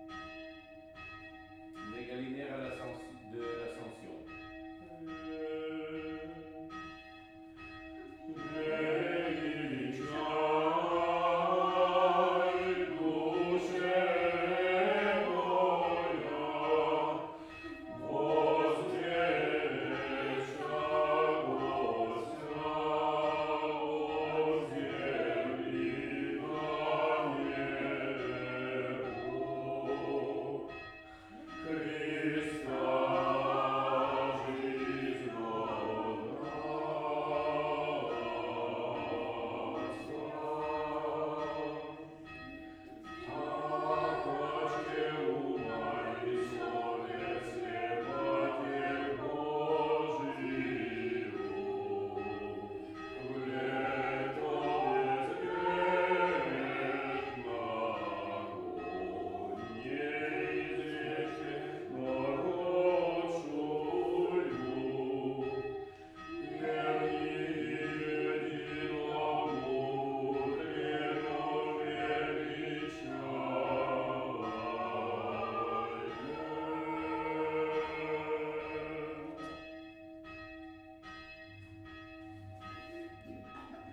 ikosascensioncloches.wav